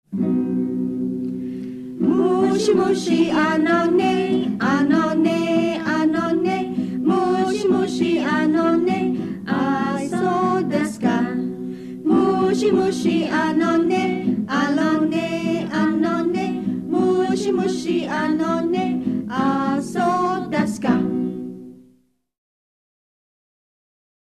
Campfire Songs